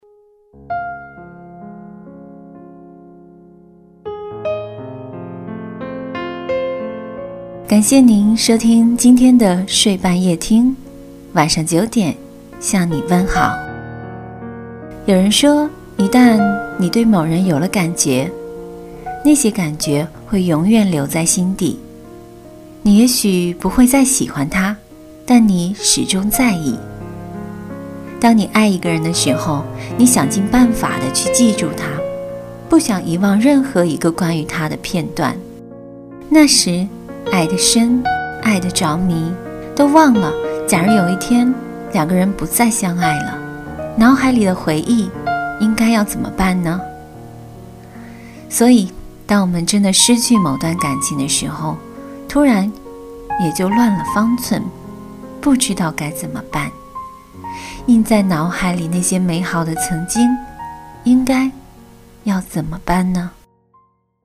女24晚上可录-纵声配音网
感情（亲和温情）
女24 感情.mp3